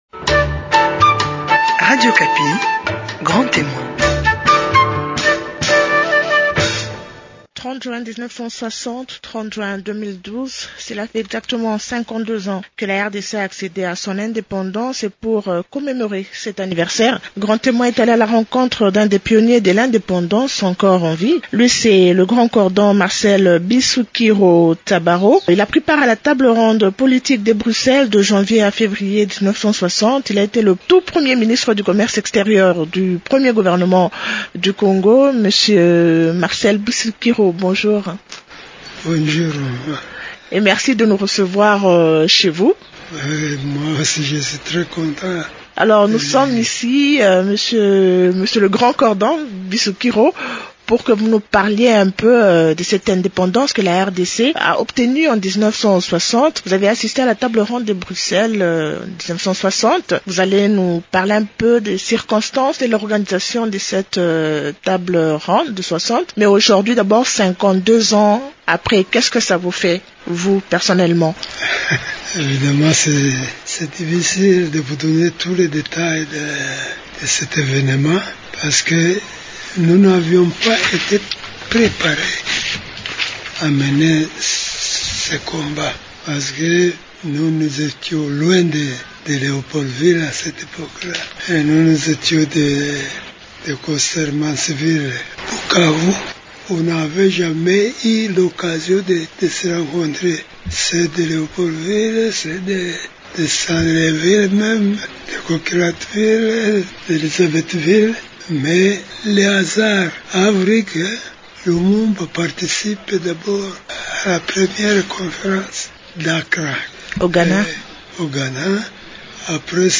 Et pour commémorer cet anniversaire, Grand Témoin est allé à la rencontre d’un des pionniers de l’indépendance.